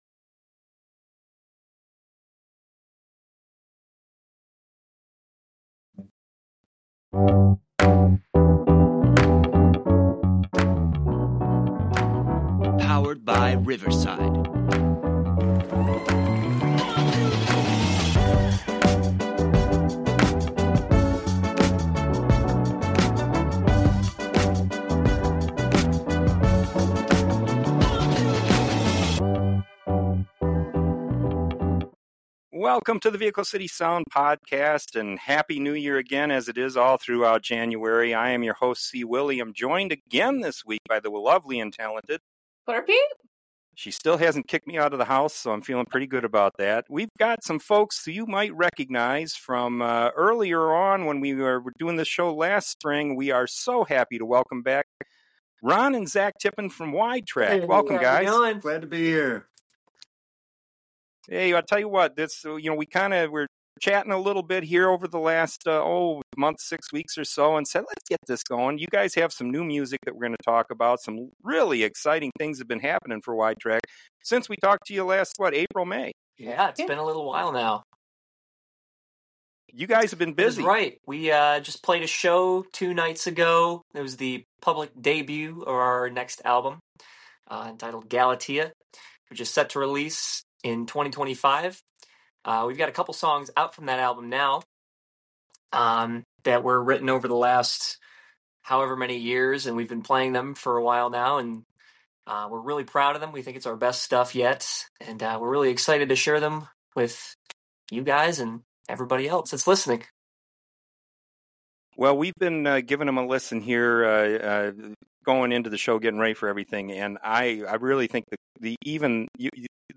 alternative/progressive rock